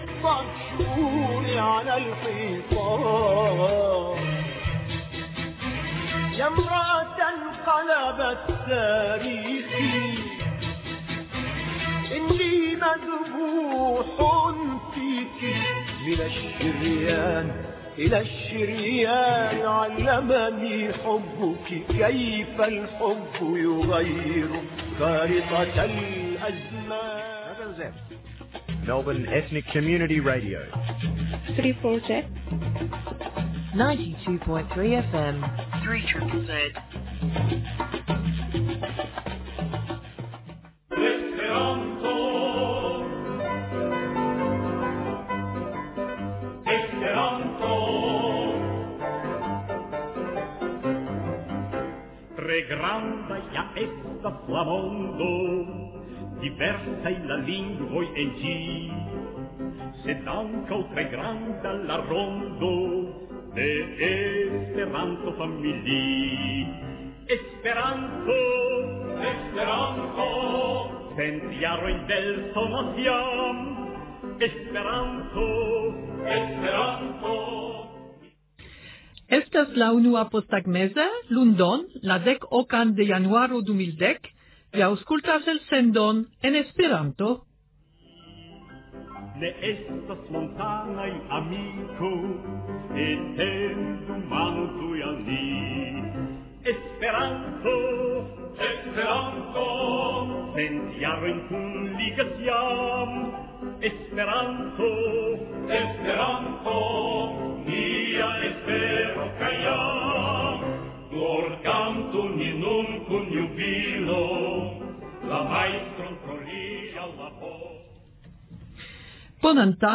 Legado
Intervjuo